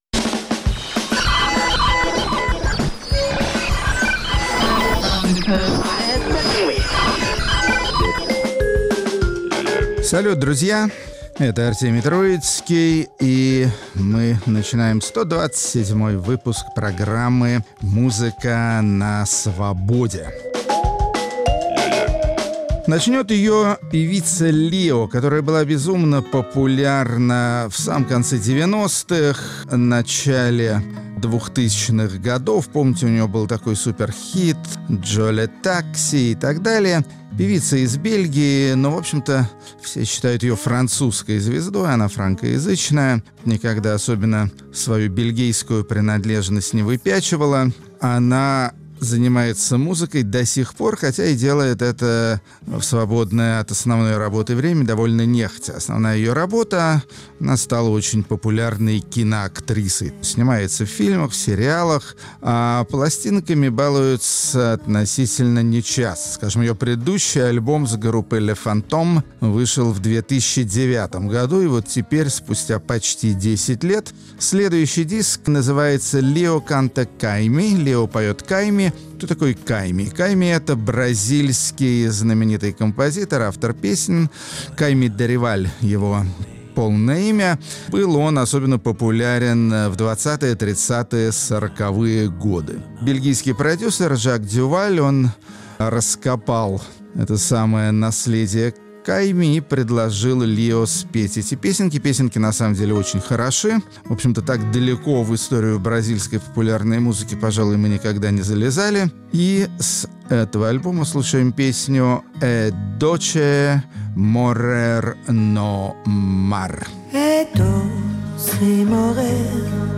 Музыка на Свободе. 8 сентября, 2019 Музыканты, производящие странные и занимательные звуки, дополняющие и развивающие концепцию их творчества. Рок-критик Артемий Троицкий убеждён в том, что разнообразие должно править в мире: чем больше выдумки и экспериментов – тем интереснее.